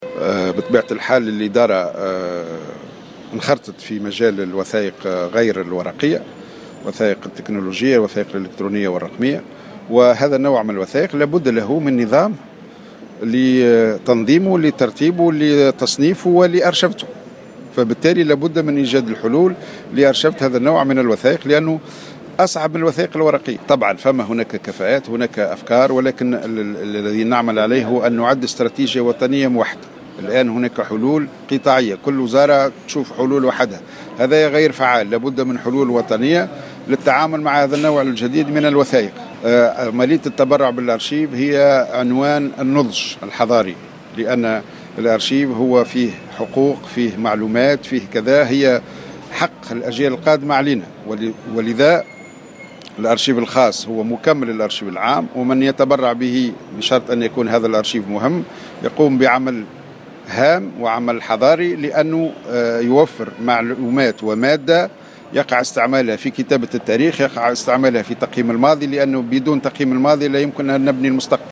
أكد المدير العام للأرشيف الهادي جلاب في تصريح لمراسل الجوهرة "اف ام" اليوم الأربعاء على هامش الإحتفال باليوم الوطني للأرشيف تحت شعار " حسن التصرف في الوثائق الالكترونية ضمان لنجاح الادارة الاتصالية" أن الإدارة انخرطت في مجال الوثائق الرقمية وهذا النوع من الوثائق لابد له من نظام لتنظيمه وترتيبه وتصنيفه وأرشفته على حد قوله.